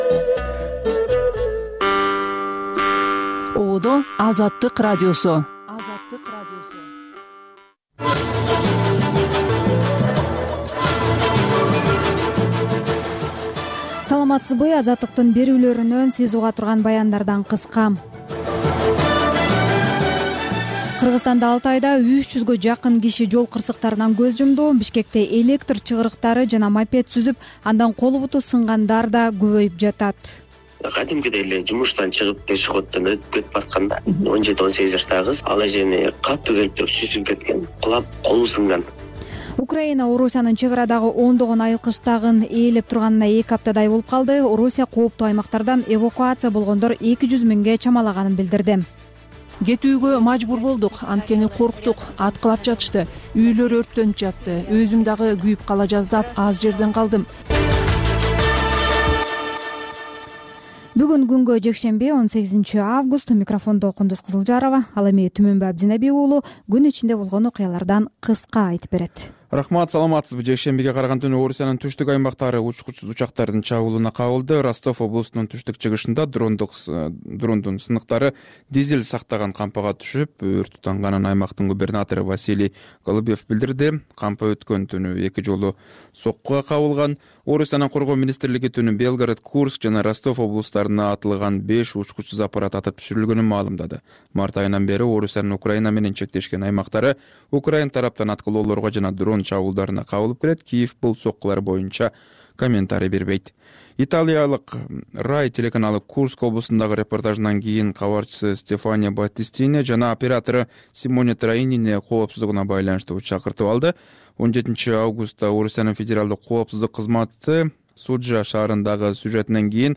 Бул үналгы берүү ар күнү Бишкек убакыты боюнча саат 19:00дөн 20:00гө чейин обого түз чыгат.